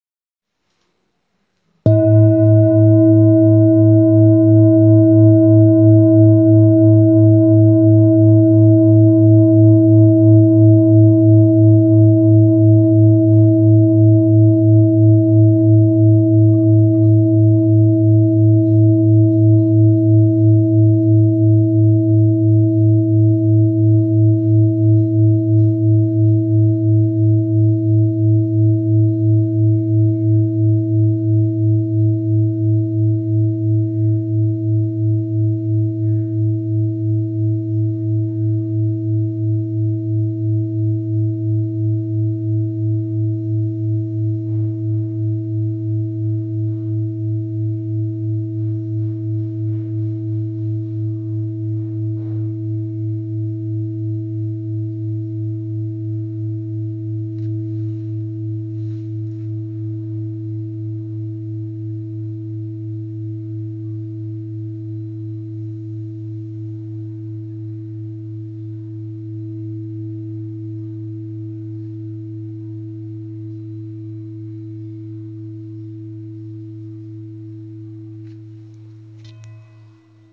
Third Eye Chakra High Quality Singing Bowl Jambati, with Chakra Symbols , A bowl used for meditation and healing, producing a soothing sound that promotes relaxation and mindfulness
Singing Bowl Ching Lu Kyogaku
Material 7 Metal Bronze
Free Third Eye Chakra [A - A# Note], 432 Hz